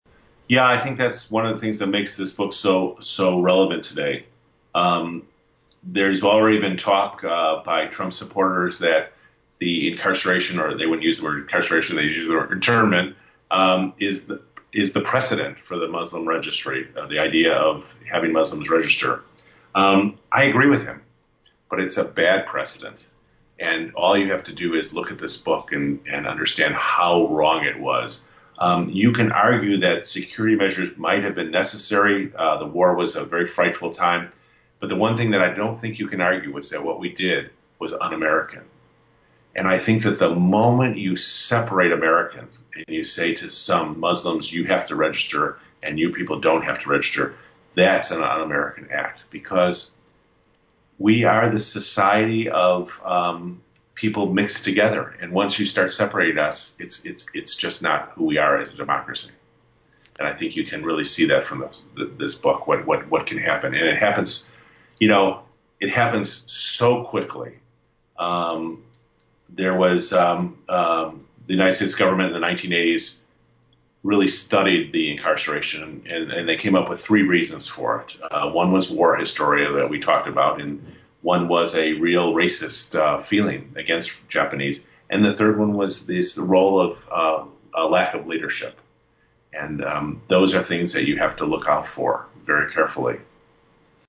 In-Depth Interview: Will America Repeat the Dark History of Japanese-American Incarceration?